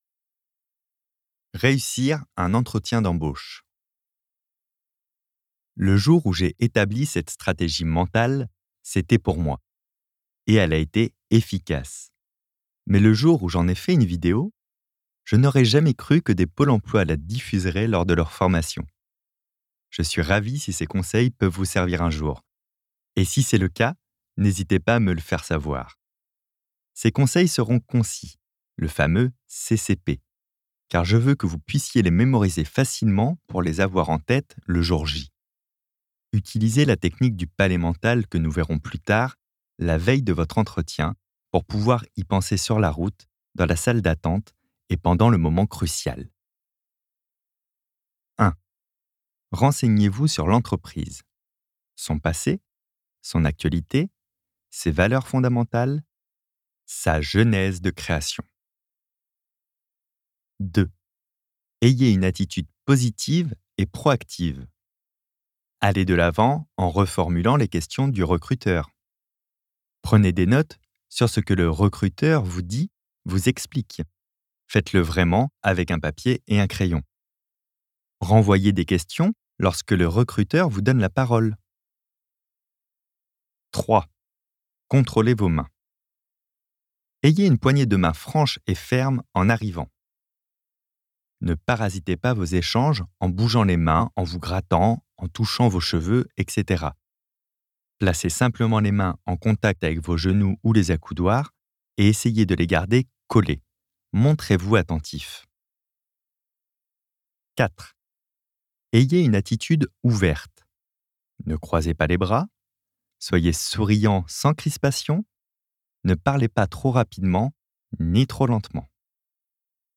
copyparty md/au/audiobook/Fabien Olicard - Votre cerveau est extraordinaire